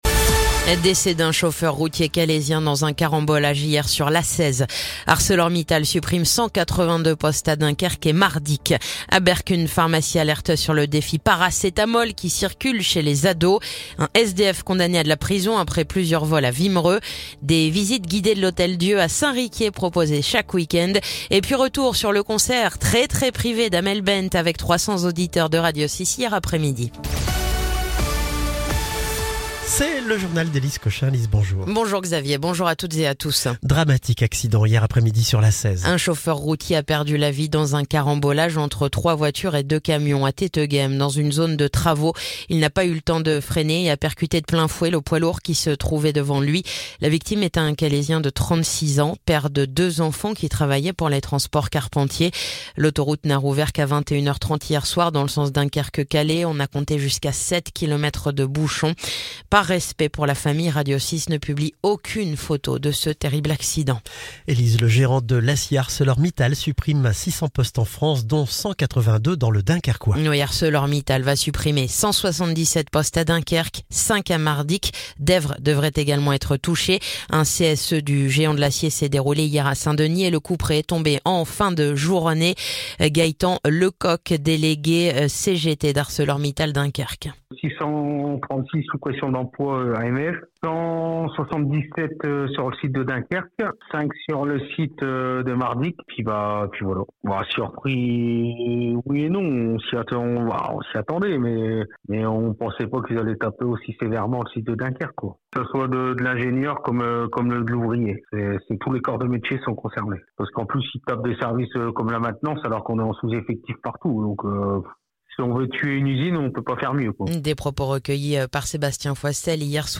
Le journal du jeudi 24 avril